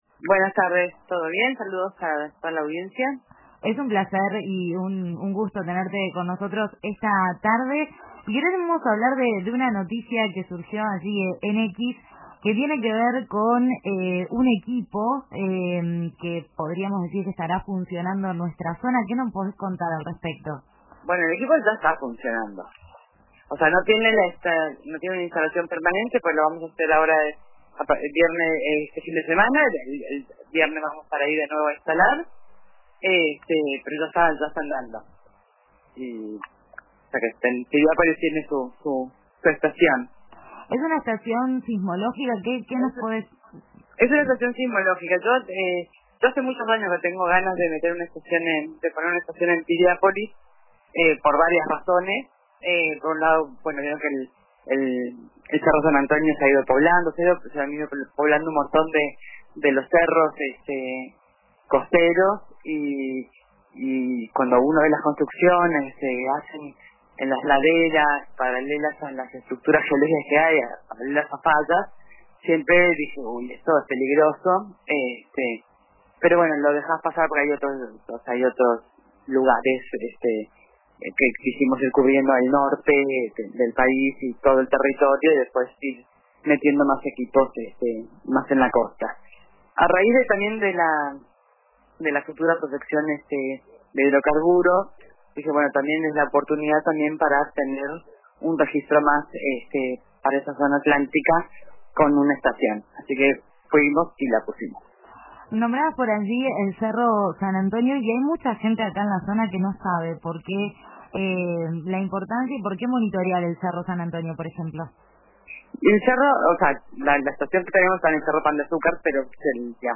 La geóloga